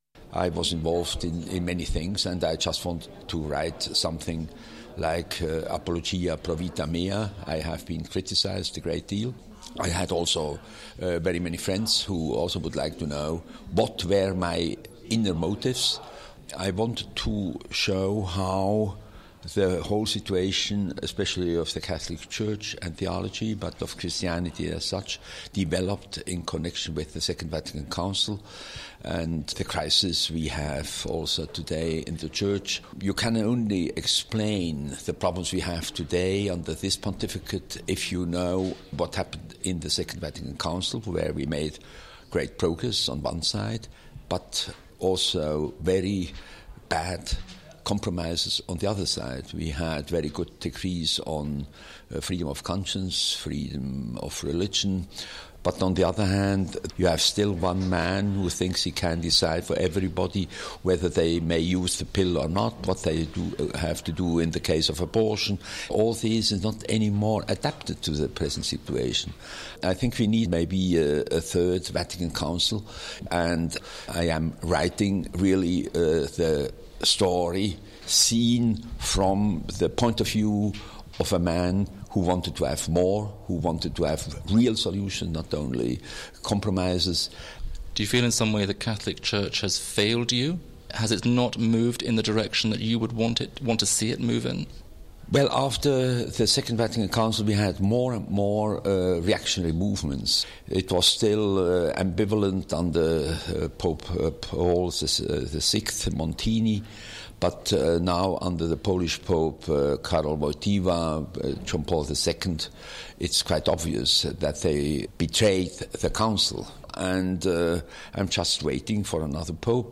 Catholic author discusses his book
Theologian Hans Küng talks about problems in the Catholic church, as well as the inspiration for his book, Hard-won Freedom.